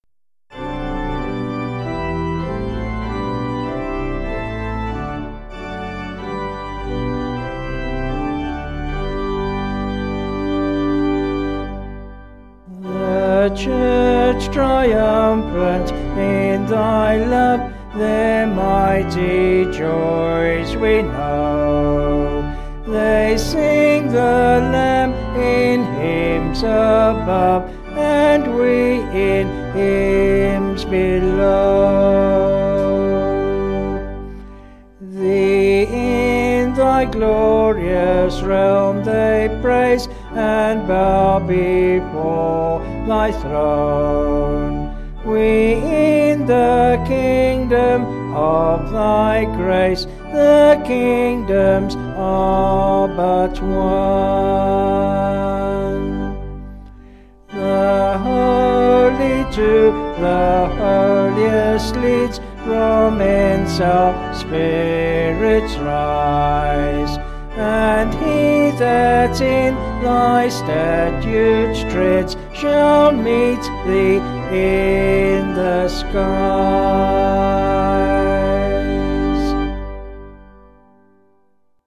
Vocals and Organ   263kb Sung Lyrics